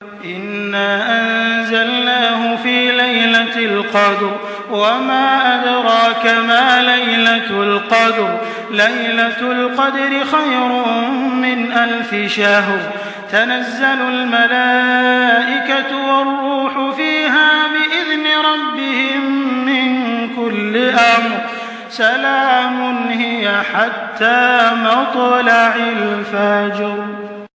تحميل سورة القدر بصوت تراويح الحرم المكي 1424
مرتل